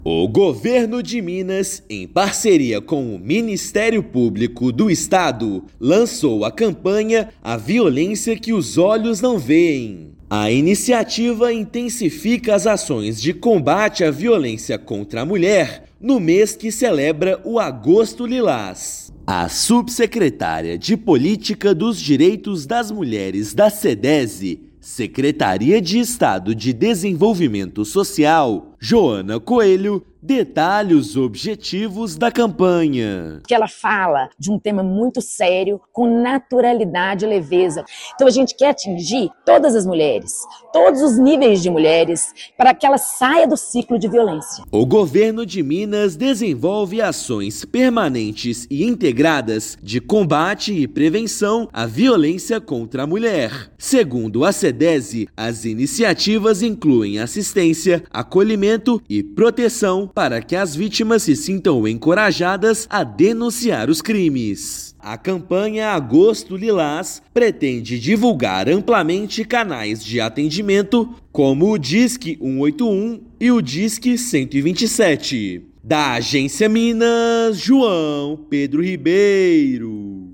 Nova campanha alerta para violências invisíveis com incentivo de denúncias. Ouça matéria de rádio.